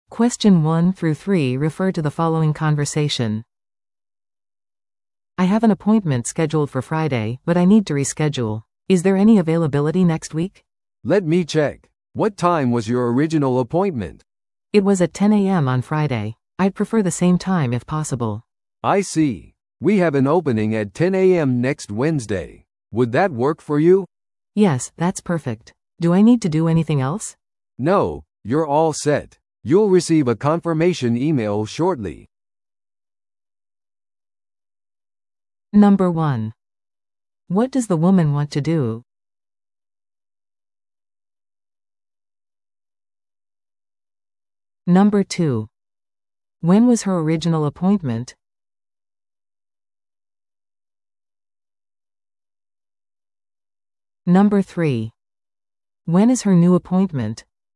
TOEICⓇ対策 Part 3｜病院の予約を変更する会話 – 音声付き No.63
No.1. What does the woman want to do?